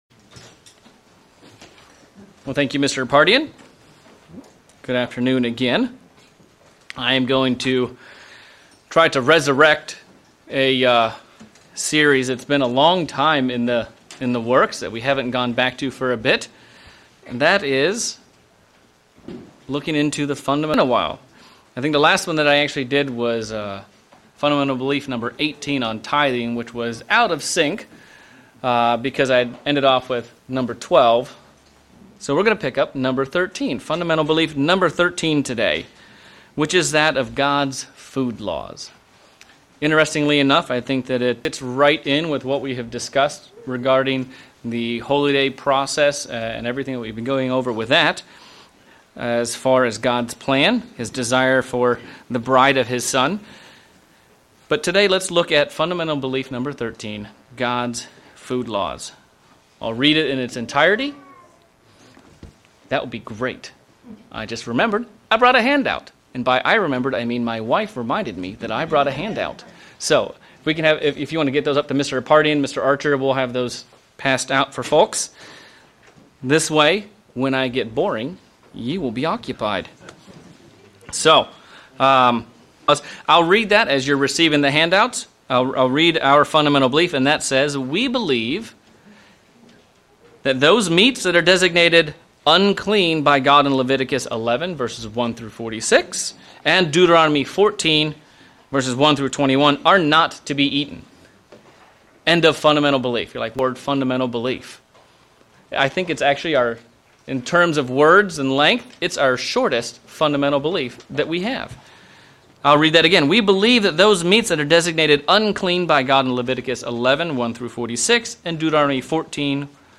Sermon looking at our fundamentals of belief #13 - God's food laws. Who decides what is holy and unholy, clean and unclean.